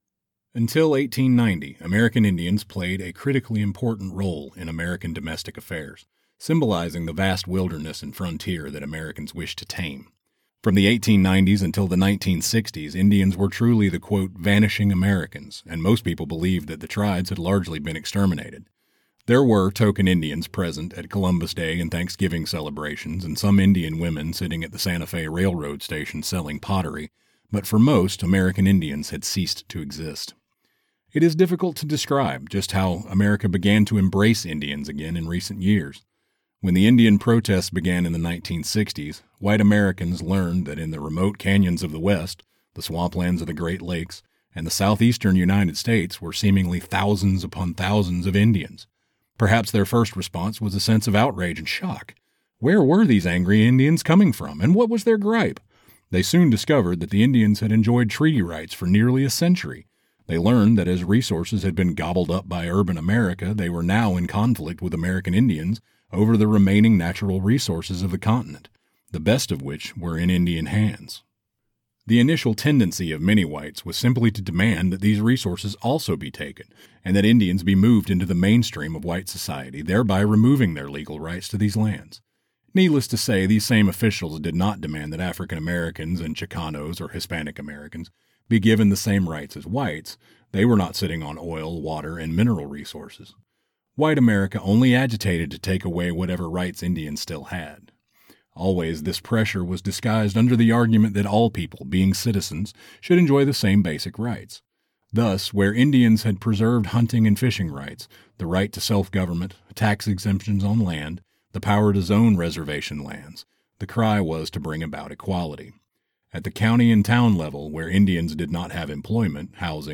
Male
Adult (30-50), Older Sound (50+)
Deep, warm, masculine, and dynamic, able to voice a full range of projects from excited and engaging to calm and authoritative.
Excerpt Of Audiobook
Words that describe my voice are warm, masculine, authoritative.
All our voice actors have professional broadcast quality recording studios.